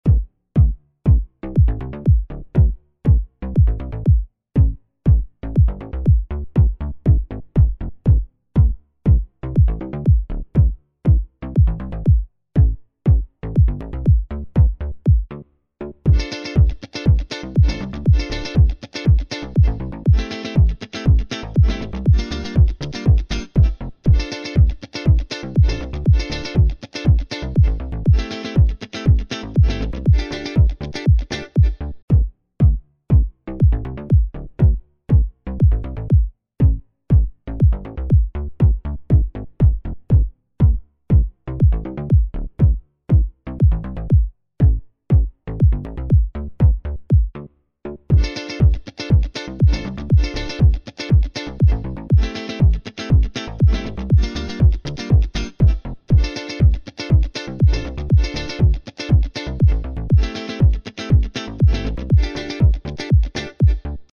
לאחרונה נחשפתי לסמפלים של חברה מסוימת והחלטתי להפיק מהם משהו כשאני מפרק את הסמפלים ובונה מהם דבר שלם.
הרצועה שלפניכם הינה רצועה שמורכבת מהרבה סגנונות שמשתלבים האחד עם השני...